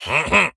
Minecraft Version Minecraft Version snapshot Latest Release | Latest Snapshot snapshot / assets / minecraft / sounds / mob / vindication_illager / idle1.ogg Compare With Compare With Latest Release | Latest Snapshot